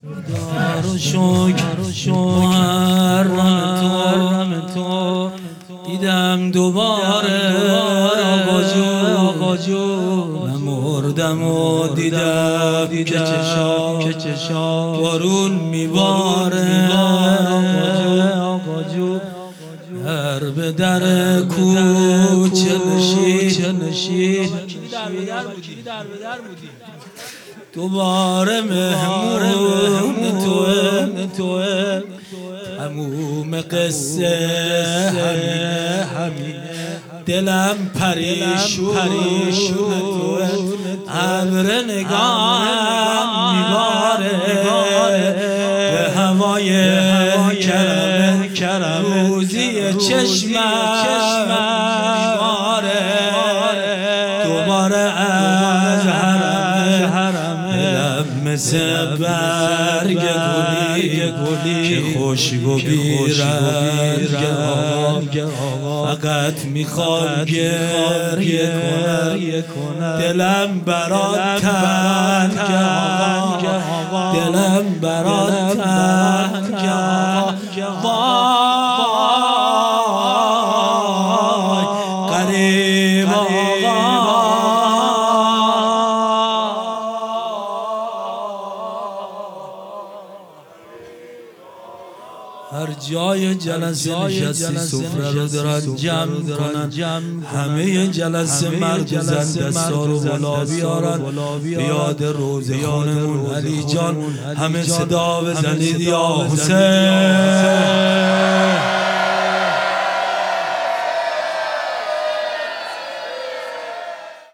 خیمه گاه - هیئت محبان الحسین علیه السلام مسگرآباد - روضه پایانی
هیئت محبان الحسین علیه السلام مسگرآباد